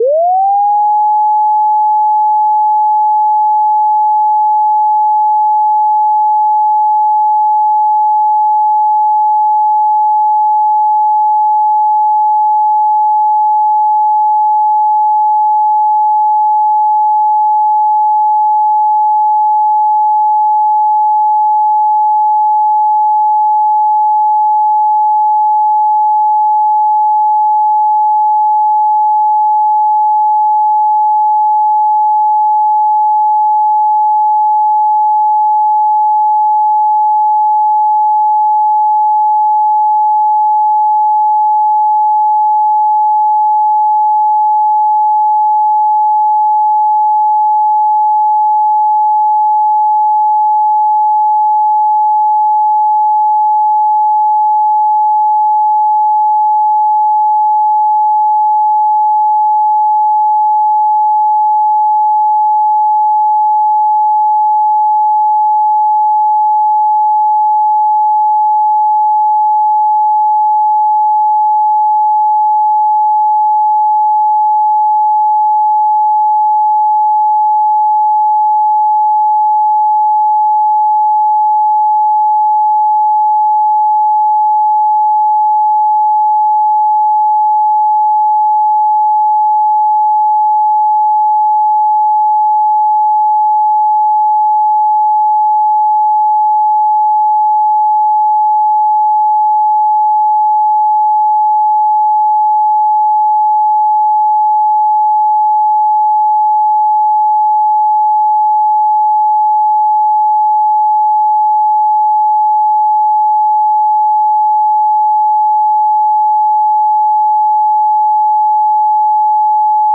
852 Hz Tone Sound Solfeggio Frequency
Solfeggio Frequencies